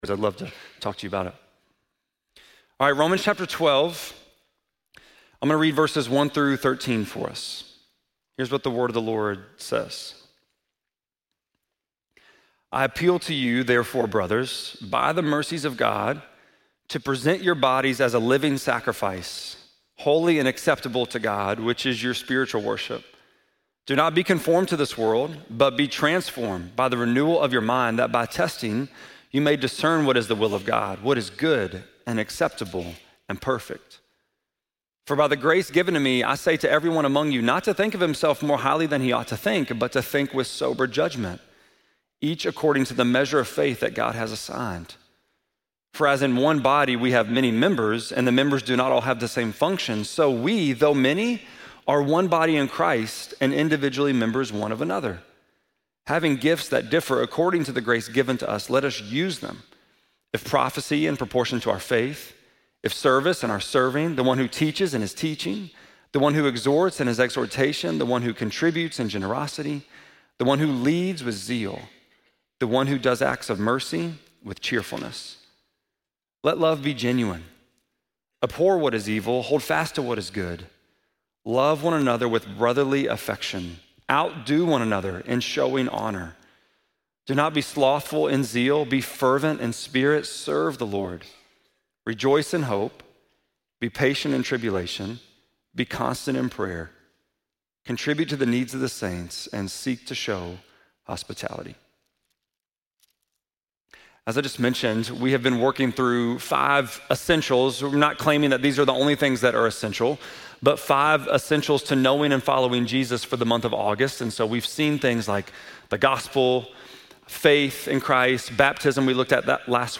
8.24-sermon.mp3